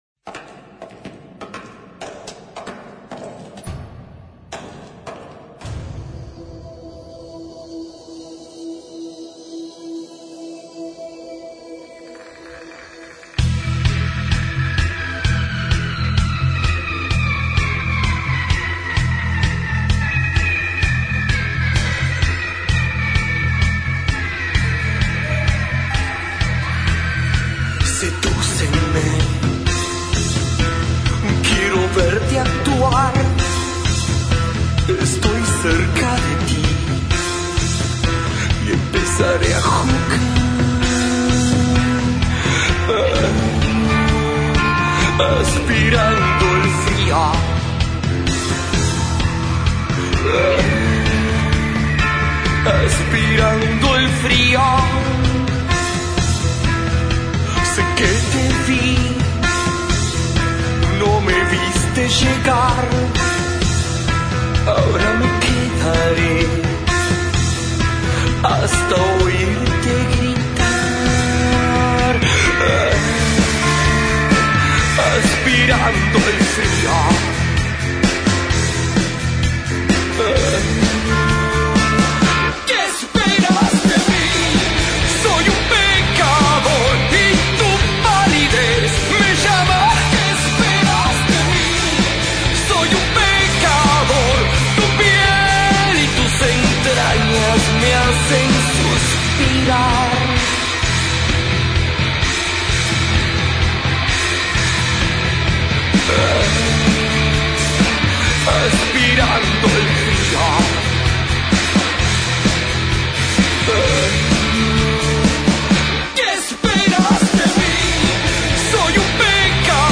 entrevistas centrales de rock al rock experience